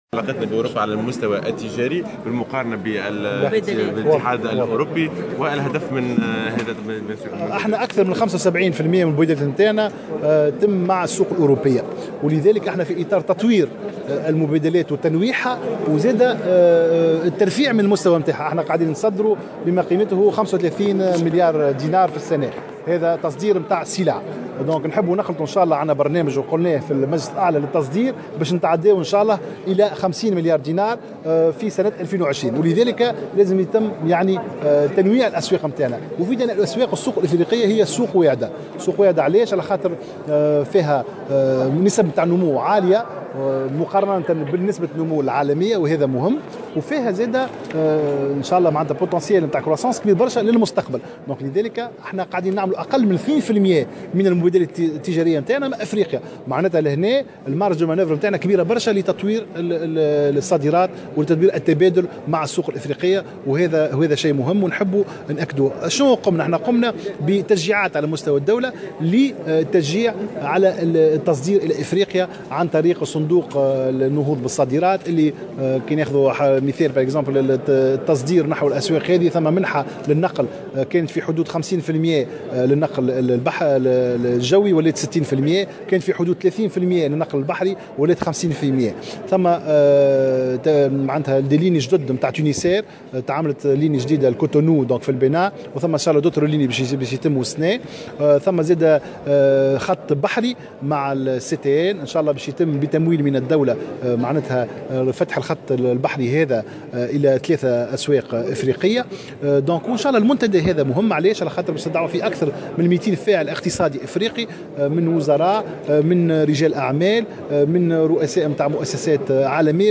واعتبر في تصريح لمراسلة "الجوهرة أف أم" على هامش ندوة للاعلان عن المنتدى الاقتصادي الافريقي، ان السوق الافريقية تبقى سوقا واعدة، معلنا عن فتح خط بحري باتجاه 3 أسواق افريقية بدعم من الدولة بالاضافة الى فتح خطوط جوية جديدة هذا العام.